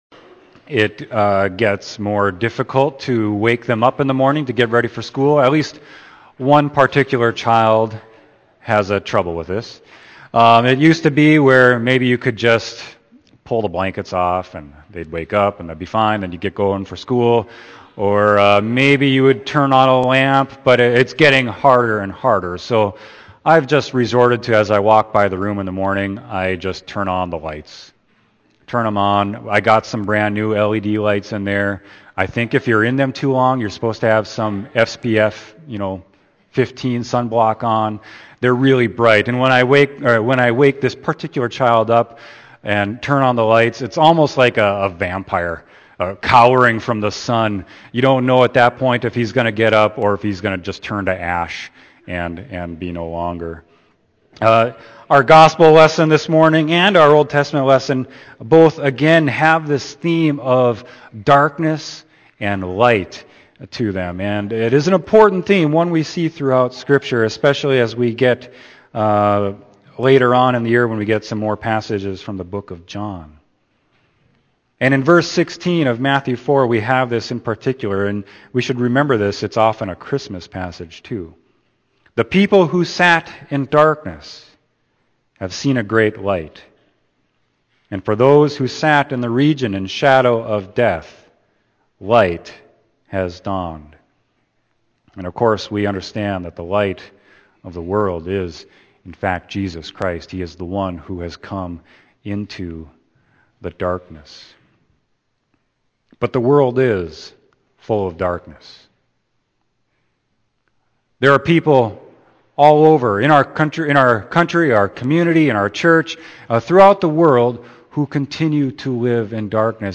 Sermon: Matthew 4.12-23